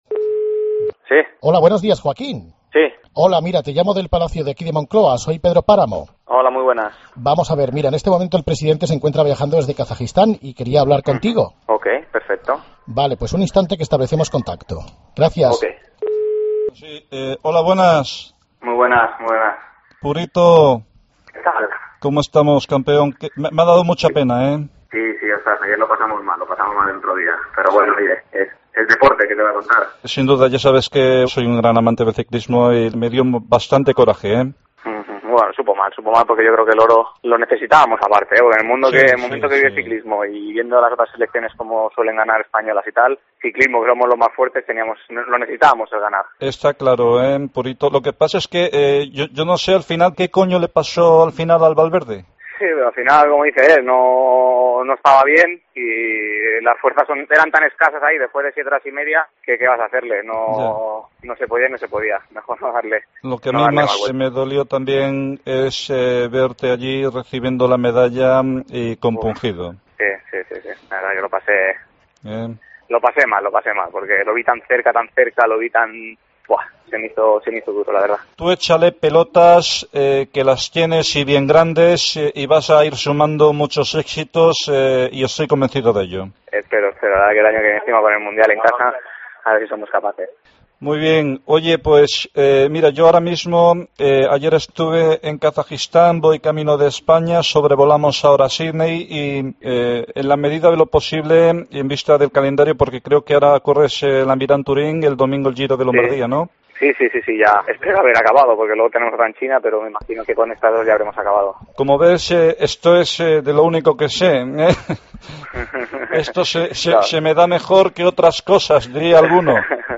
Llamada de nuestro Rajoy a Purito Rodríguez